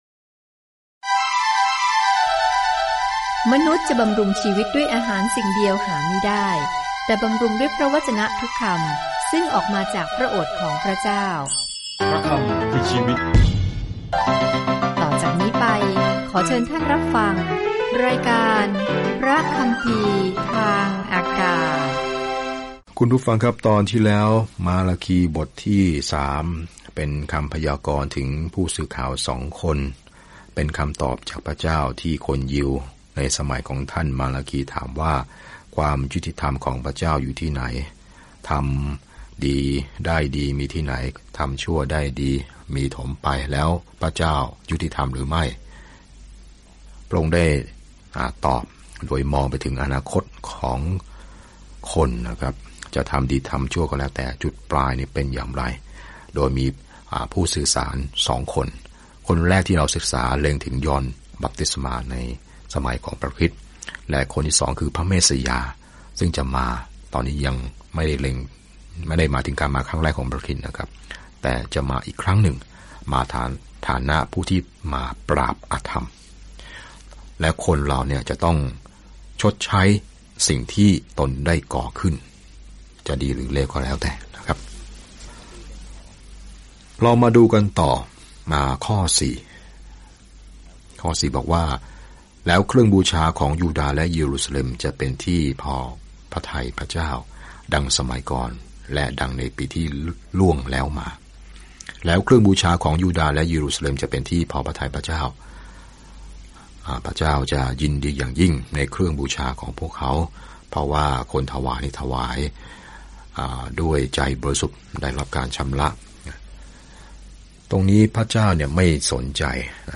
มาลาคีเตือนชาวอิสราเอลที่ขาดการเชื่อมต่อว่าเขาได้รับข้อความจากพระเจ้าก่อนที่พวกเขาจะอดทนต่อความเงียบอันยาวนาน ซึ่งจะจบลงเมื่อพระเยซูคริสต์เสด็จเข้าสู่เวที เดินทางทุกวันผ่านมาลาคีในขณะที่คุณฟังการศึกษาด้วยเสียงและอ่านข้อที่เลือกจากพระวจนะของพระเจ้า